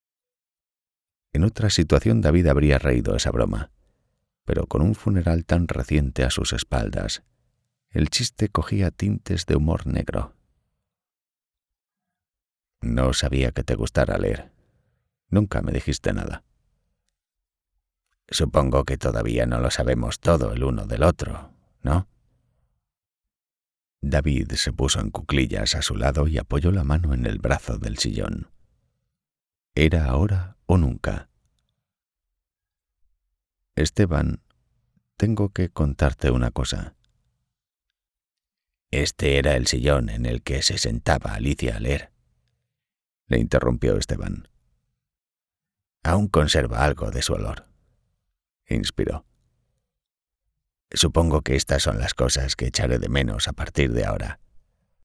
Adult (30-50) | Older Sound (50+)
1106Audiolibro_-_El_paso_de_la_helice.mp3